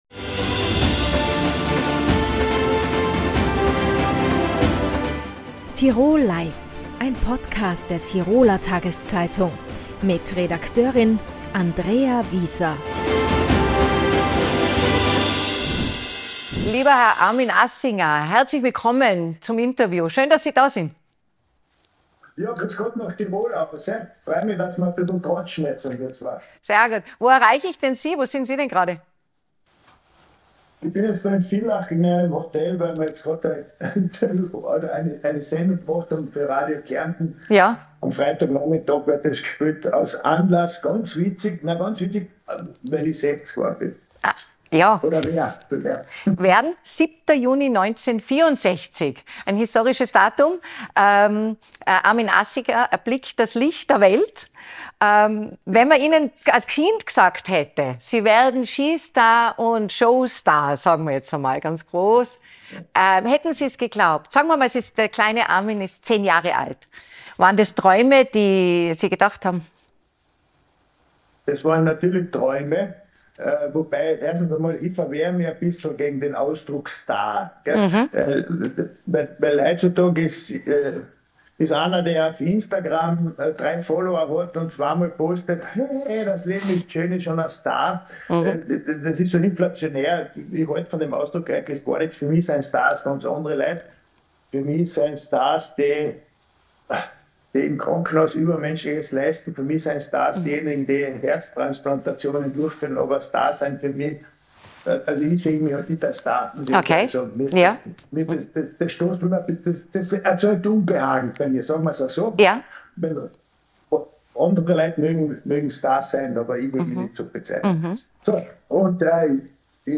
TV-Liebling im Gespräch ~ Tirol Live Podcast